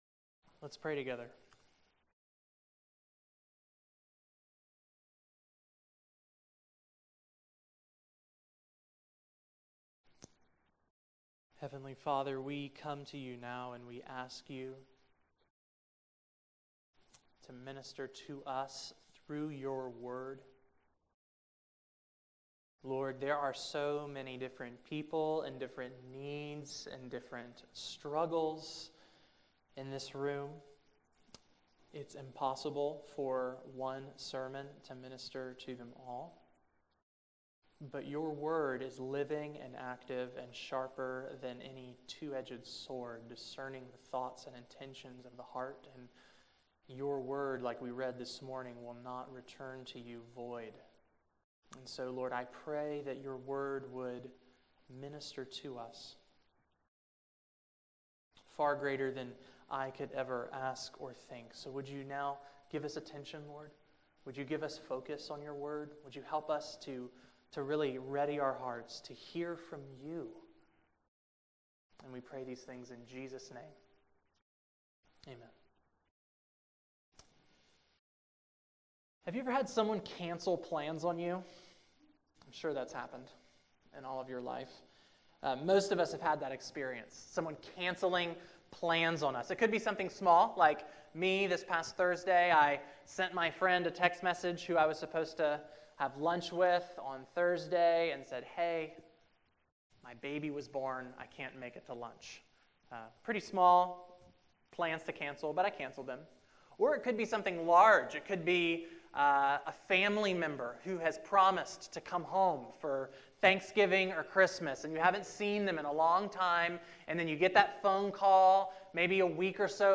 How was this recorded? September 25, 2016 Morning Worship | Vine Street Baptist Church